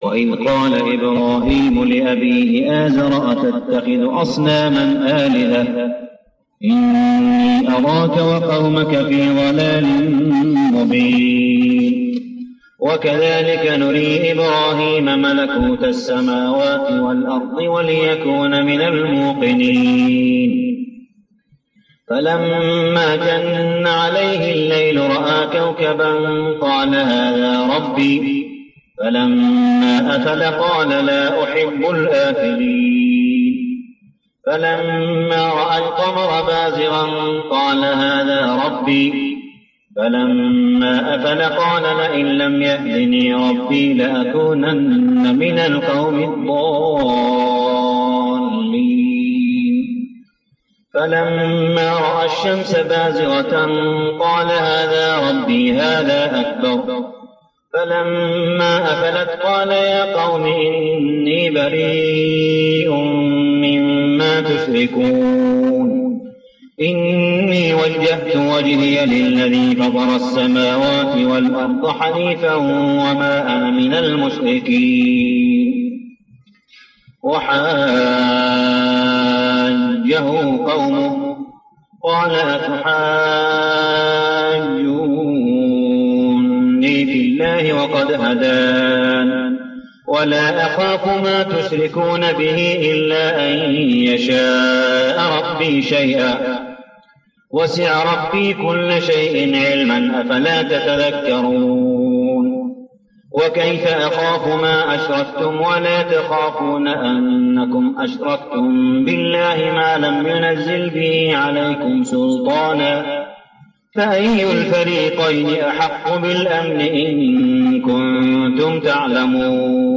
صلاة التراويح ليلة 8-9-1412هـ سورة الأنعام 74-150 | Tarawih prayer Surah Al-An'am > تراويح الحرم المكي عام 1412 🕋 > التراويح - تلاوات الحرمين